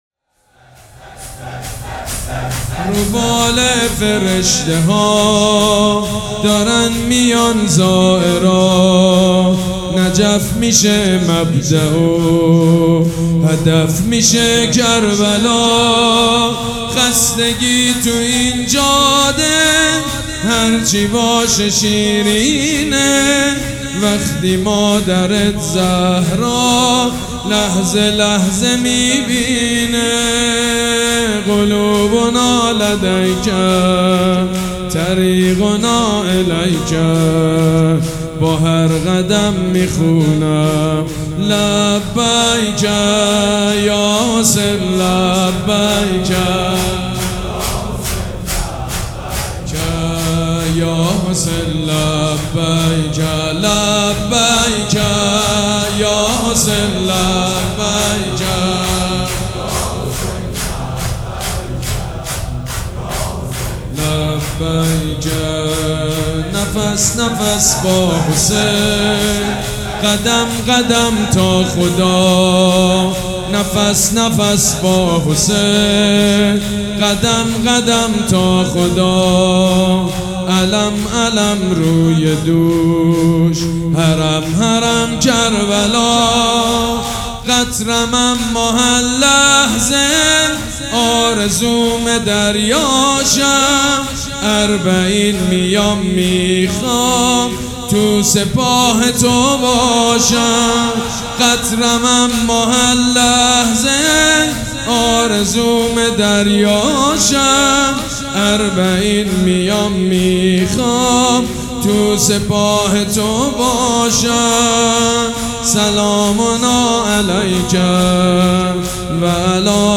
شور
حاج سید مجید بنی فاطمه
مراسم عزاداری شب چهارم
shoor.mp3